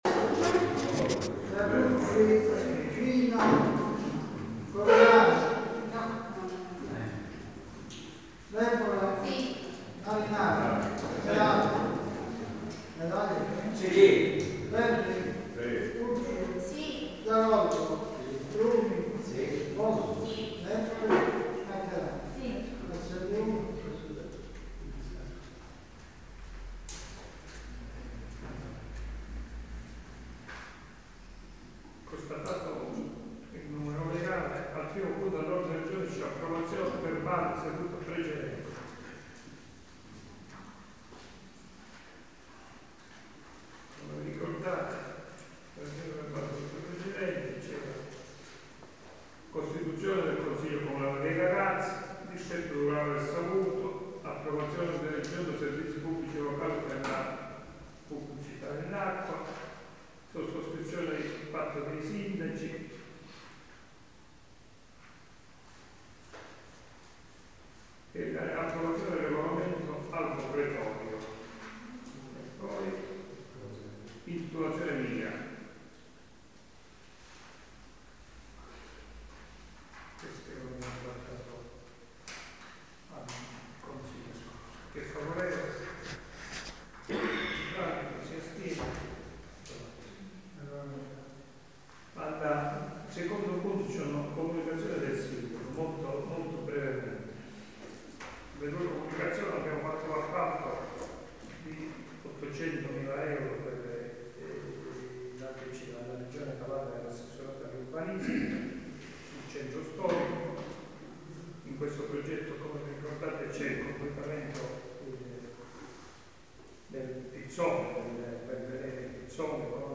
Consiglio Comunale - Aiello Calabro 2 maggio 2011.mp3